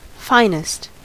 Ääntäminen
Ääntäminen US Haettu sana löytyi näillä lähdekielillä: englanti Käännöksiä ei löytynyt valitulle kohdekielelle. Finest on sanan fine superlatiivi.